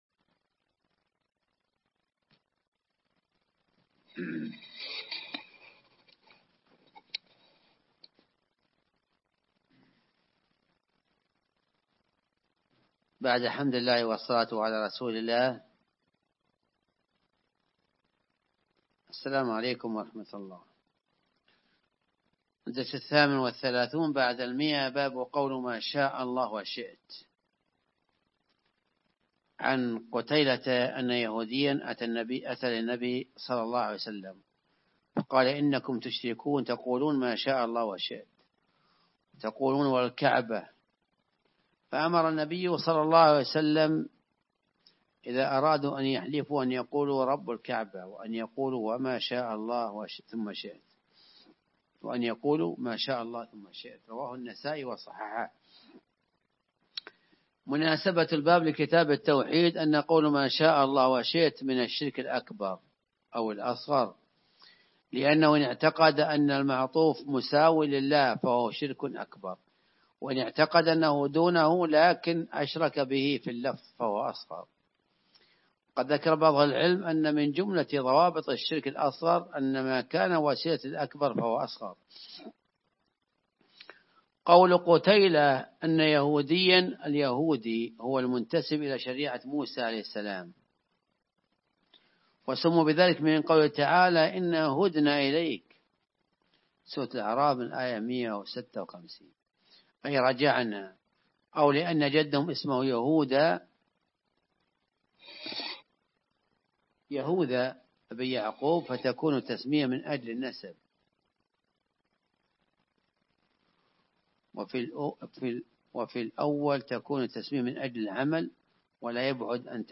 الدرس الثامن والثلاثون بعد المائة: باب قول ما شاء الله وشئت